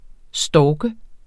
Udtale [ ˈsdɒːgə ]